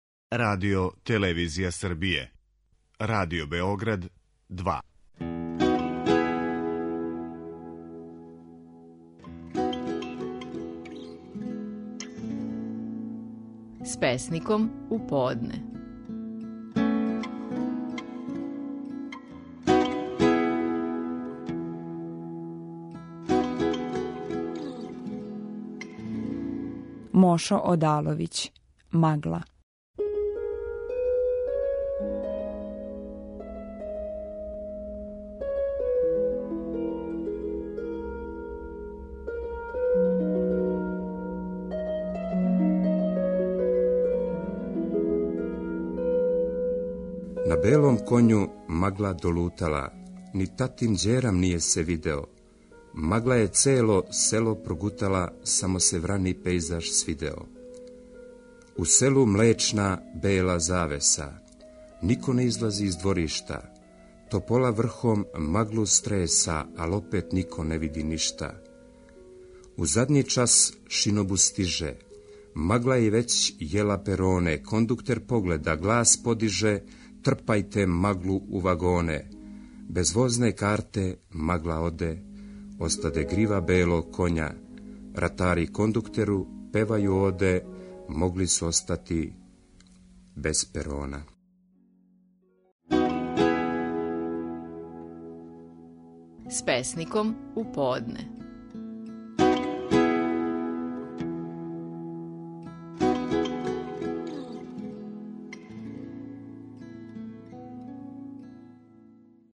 Стихови наших најпознатијих песника, у интерпретацији аутора
Мошо Одаловић говори своју песму: „Магла".